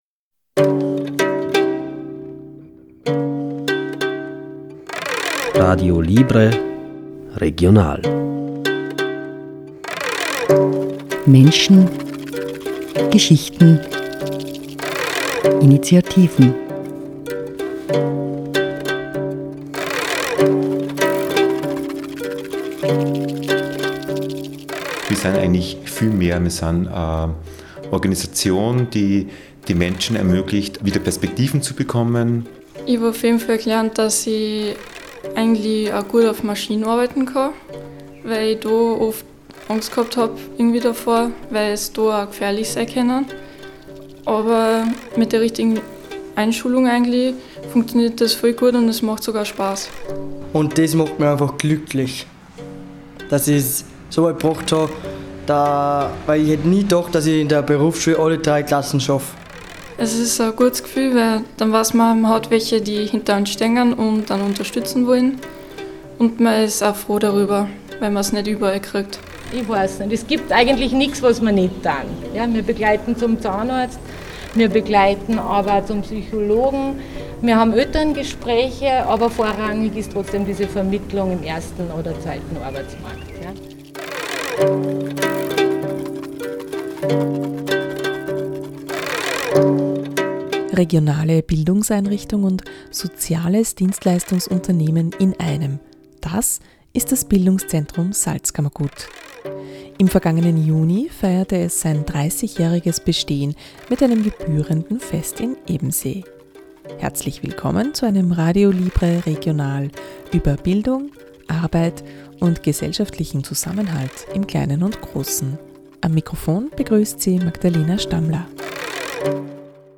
Im vergangenen Juni feierte es sein 30-jähriges Bestehen mit einem gebührenden Fest in Ebensee. Wir waren vor Ort und haben viele der Mitarbeiter:innen und Projektteilnehmer:innen des BIS zum Gespräch getroffen. Ein Radio Libre Regional über Bildung, Arbeit und gesellschaftlichen Zusammenhalt im Kleinen und Großen.